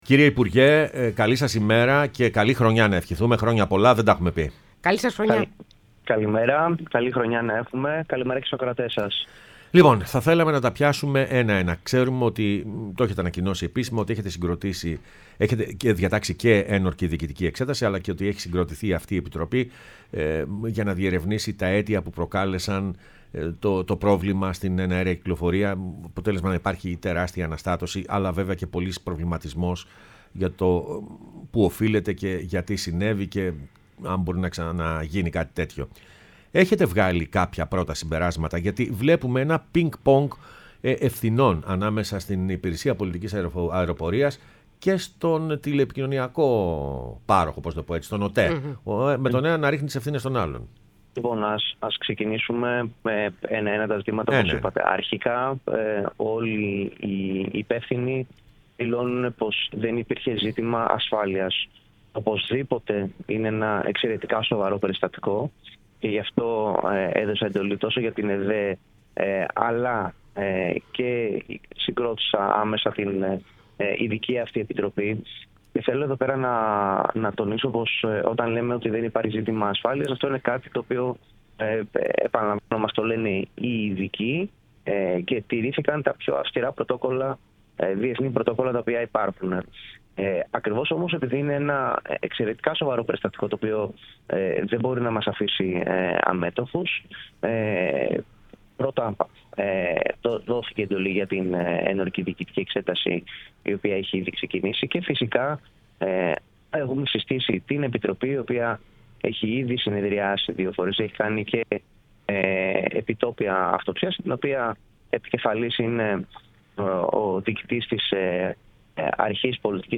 O Χρήστος Δήμας, Υπουργός Μεταφορών, μίλησε στην εκπομπή Πρωινές Διαδρομές